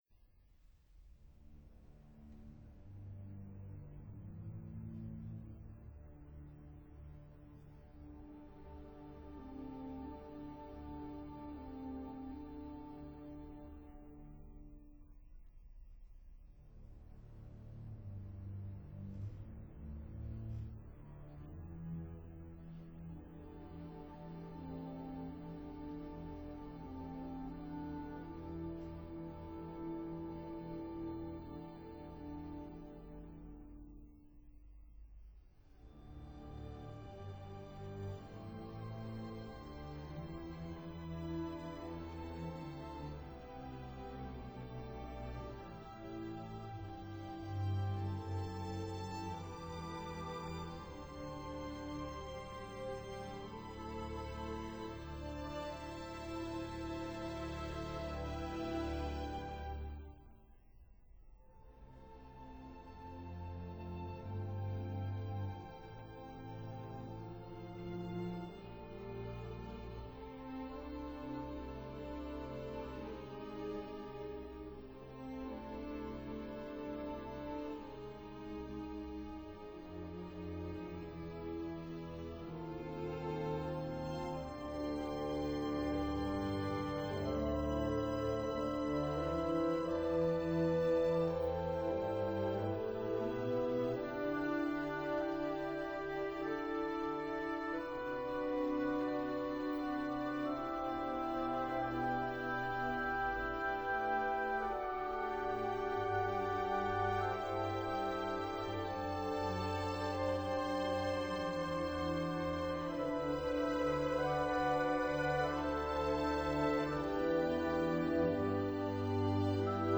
pianos